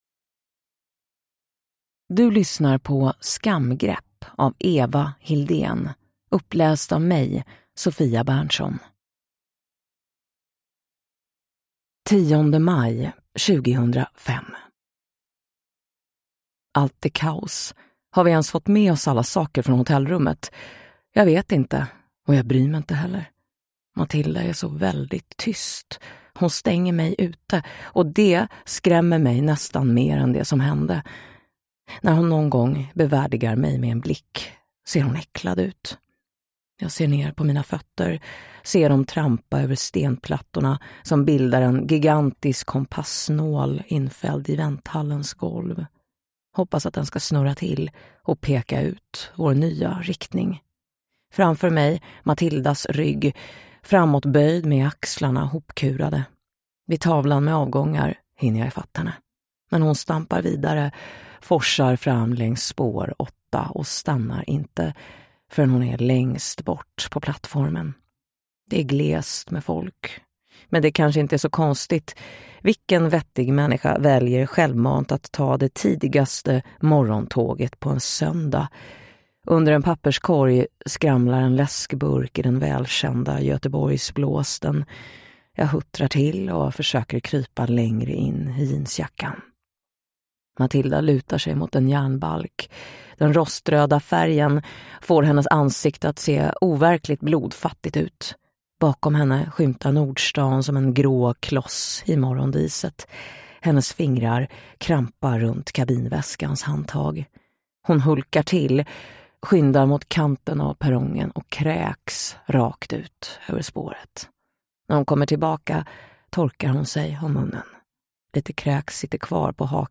Skamgrepp (ljudbok) av Eva Hildén